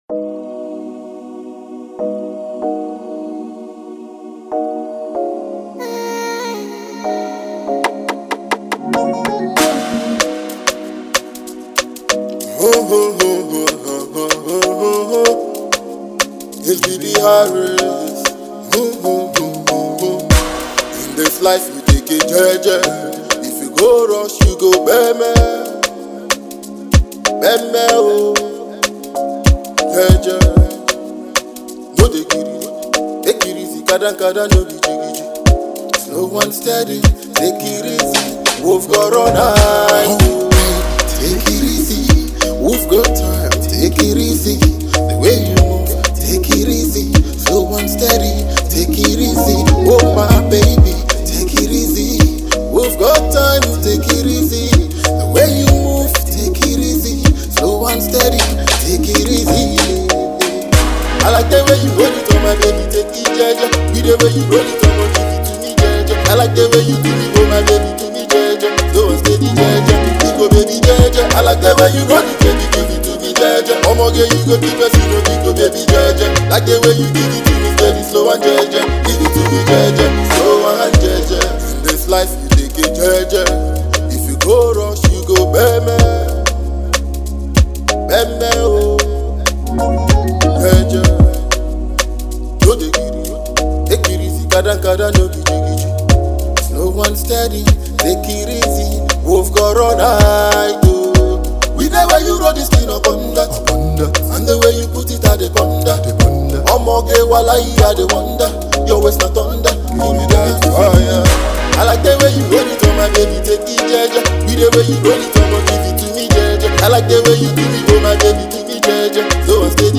groovy song